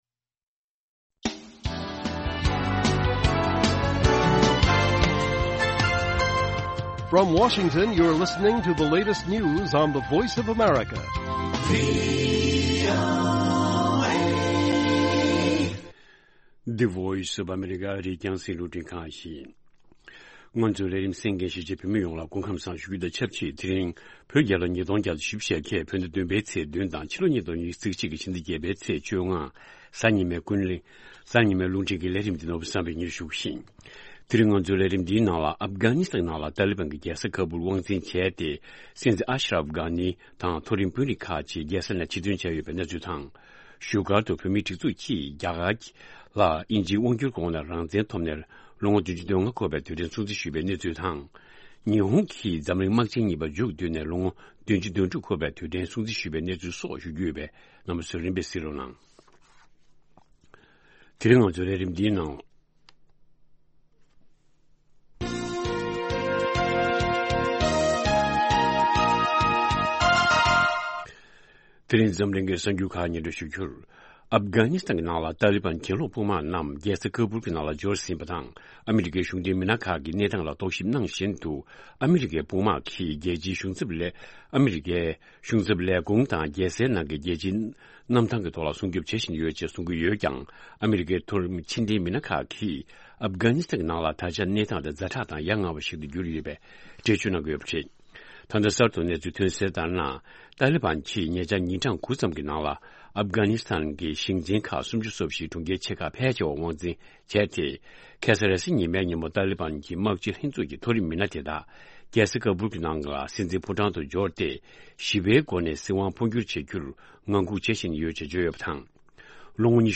སྔ་དྲོའི་རླུང་འཕྲིན།